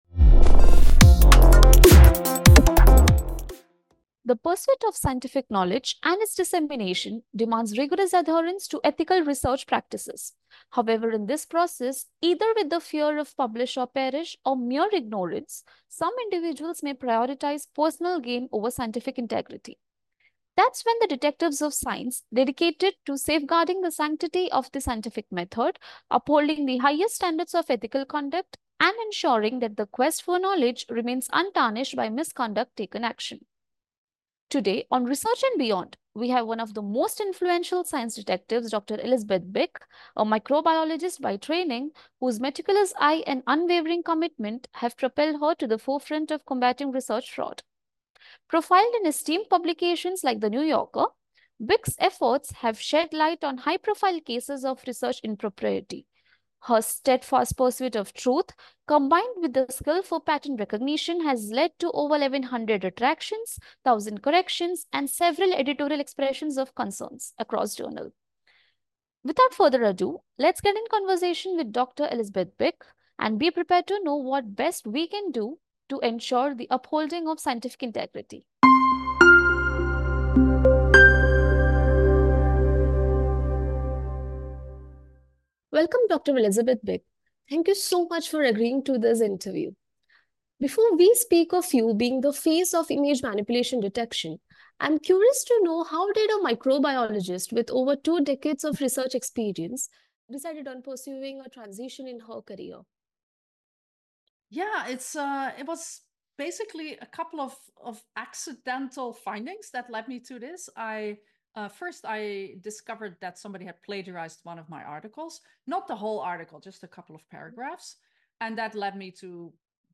This candid conversation delves into her journey from academia to becoming a global advocate for research integrity, unraveling the challenges and triumphs of combating scientific misconduct.